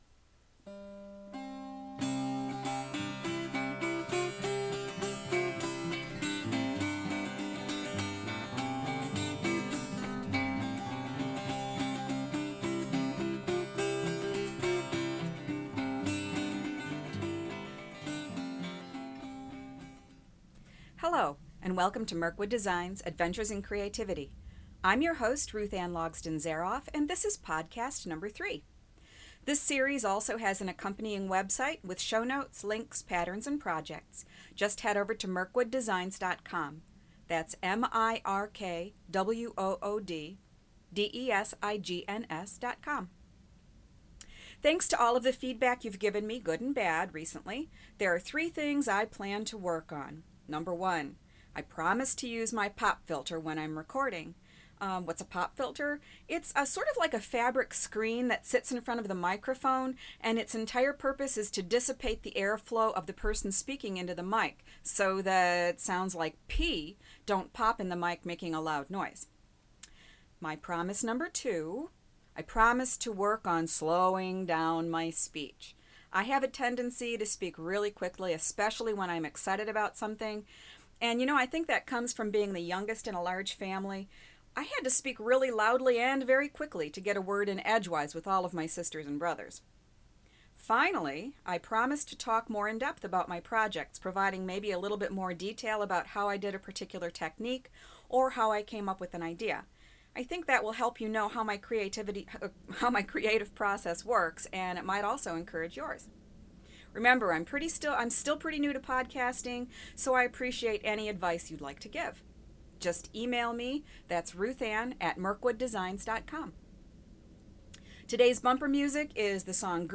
LOL! I tried to slow down!
INTRODUCTION Today's bumper music is Greensleeves, a popular 16th century ballad.